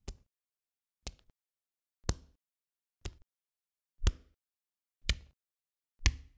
描述：一滴水
标签： 水滴
声道单声道